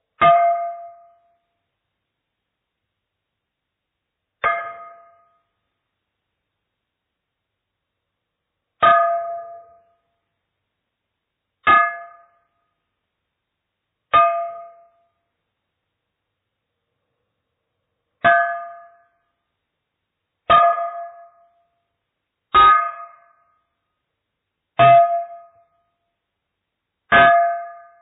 Glass Bottle Striking Slowed
bonk bottle clash crash ding glass hammer hit sound effect free sound royalty free Sound Effects